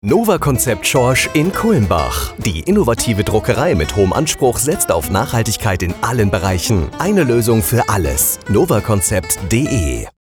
Radiospot auf Antenne Bayern
Funkspot_NOVACONCEPT-SCHORSCH-GMBH-10-Sek.mp3